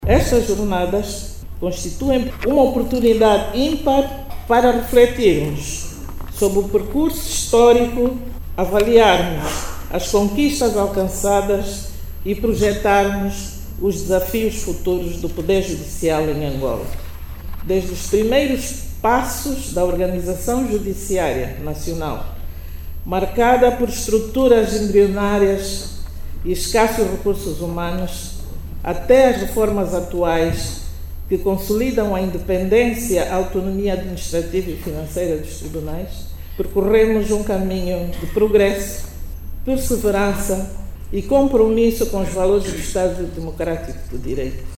A Presidente Interina do Tribunal Supremo, Efigénia Clemente, afirmou que as Jornadas Técnico-Científicas constituem uma oportunidade para reflectir o percurso histórico da Instituição e projectar os desafios futuros do poder judicial em Angola.